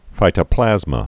(fītə-plăzmə)